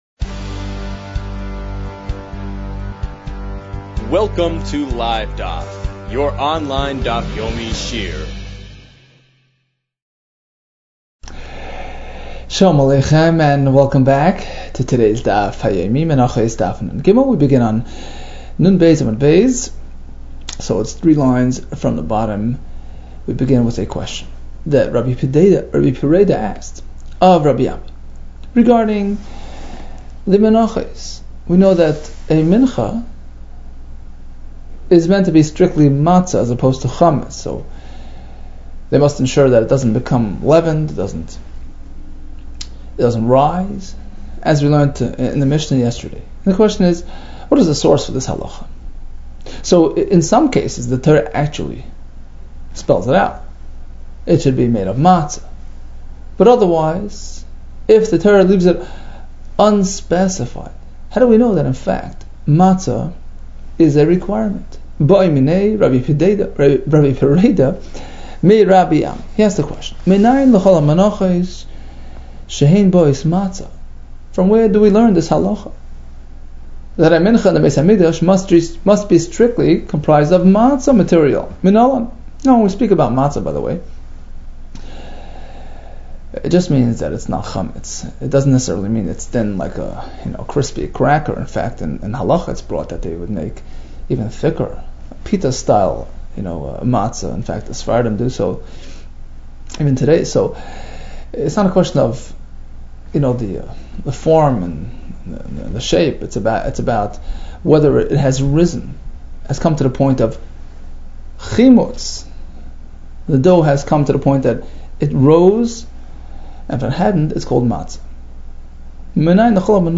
Menachos 53 - מנחות נג | Daf Yomi Online Shiur | Livedaf